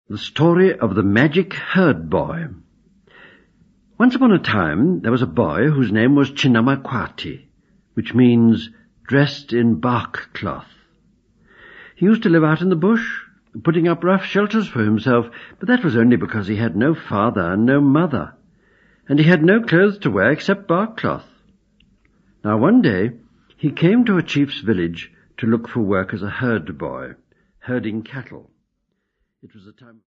Story
Original format: 15ips reel